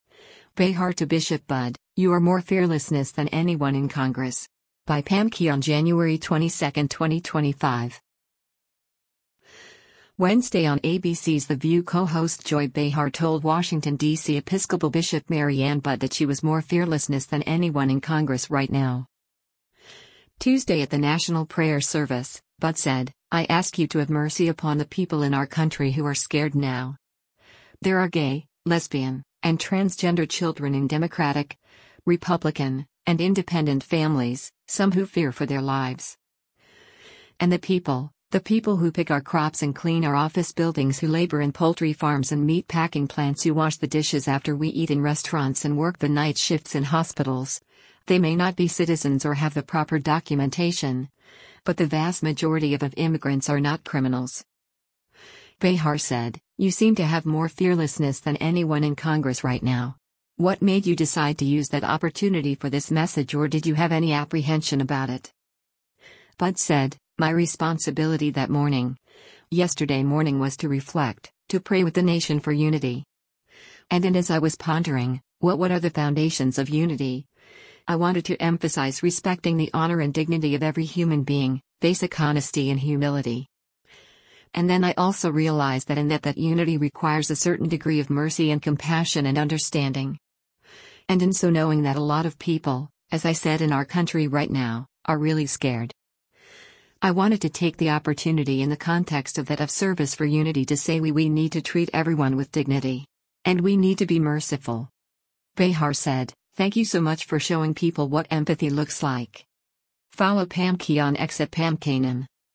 Wednesday on ABC’s “The View” co-host Joy Behar told Washington, D.C. Episcopal Bishop Mariann Budde that she was “more fearlessness than anyone in Congress right now.”